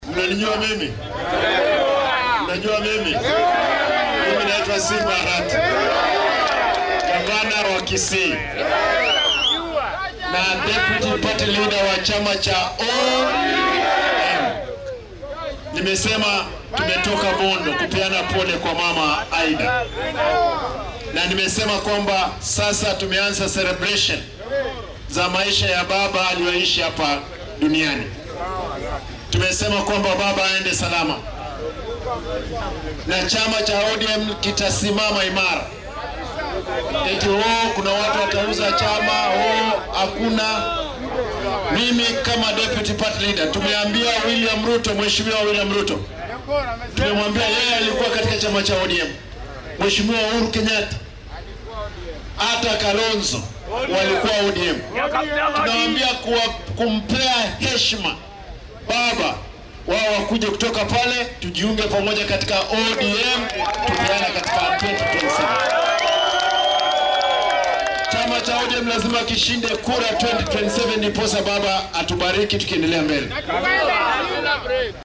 Barasaabka ismaamulka Kisii Simba Arati ayaa ka codsaday Madaxweynahii hore ee dalka Uhuru Kenyatta iyo hoggaamiyaha xisbiga Wiper Patriotic Front (WPF) Kalonzo Musyoka inay dib ugu biiraan xisbiga ODM. Isagoo Sabtidii ka hadlayay dowlad deegaanka Kisumu, Arati ayaa waxaa uu sheegay in labada hoggaamiye ay sharfi karaan Raa’iisul Wasaarihii hore ee geeriyooday Raila Odinga iyagoo dib ugu biiraya xisbiga ODM ka hor doorashada guud ee 2027.